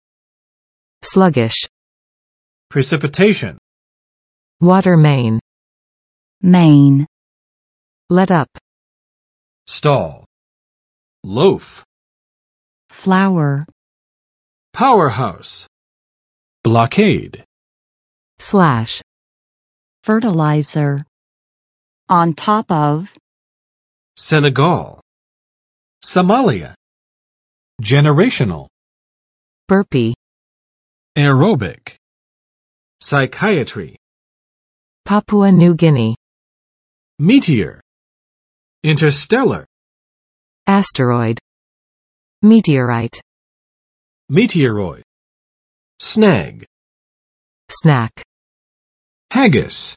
[ˋslʌgɪʃ] adj. 缓慢的，迟钝的